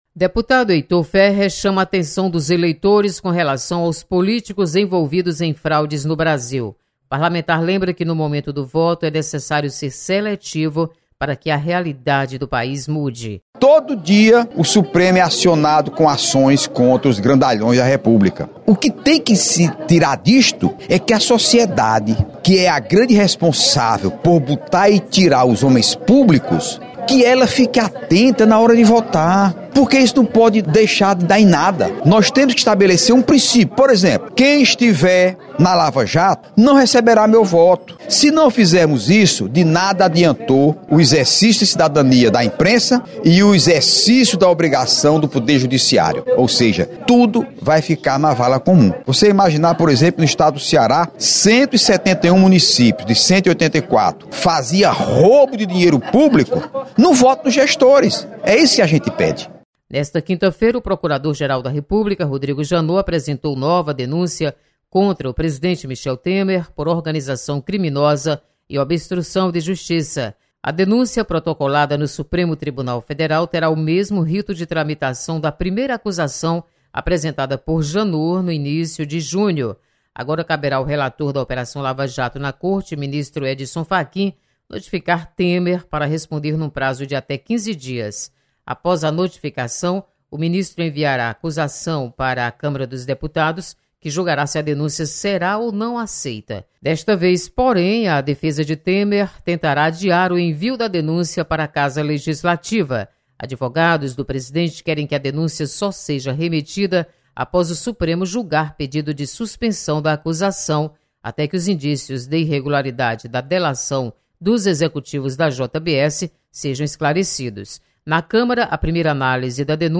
Deputados comentam sobre relação de políticos envolvidos em casos de corrupção.